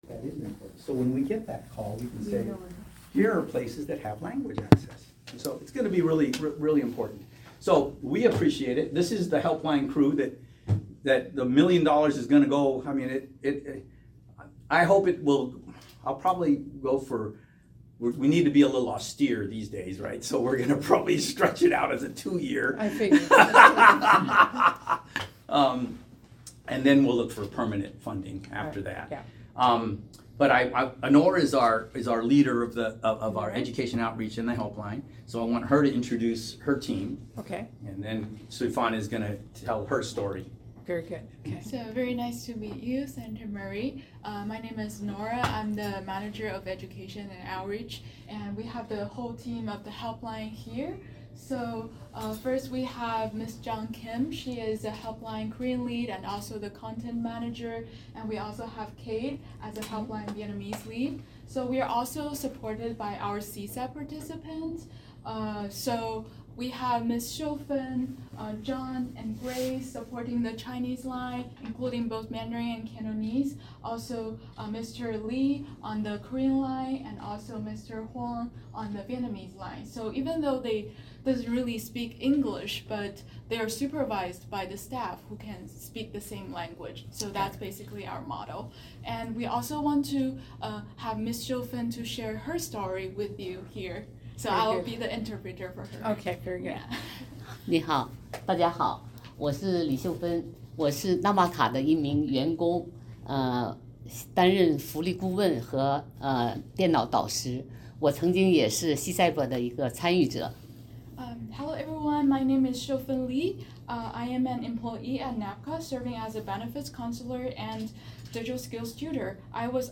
***AUDIO OF ROUNDTABLE